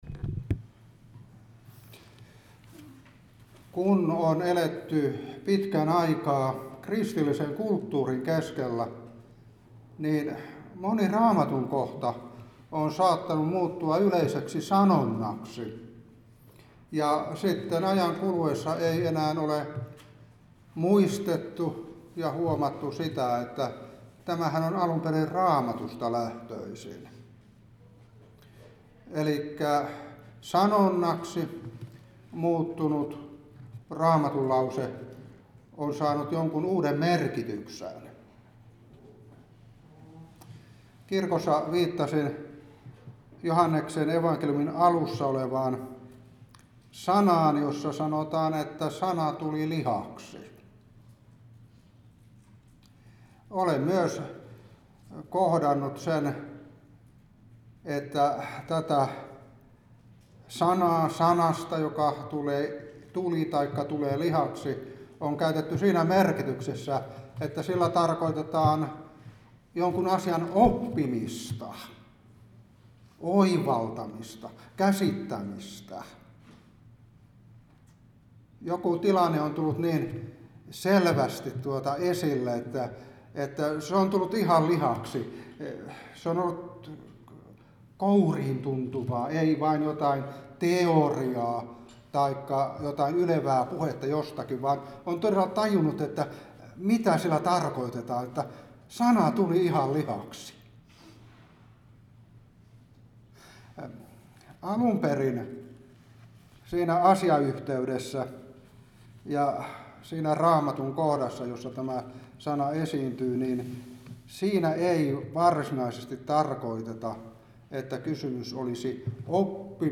Opetuspuhe 2025-3. Joh.1:14. Luuk.1:26-38.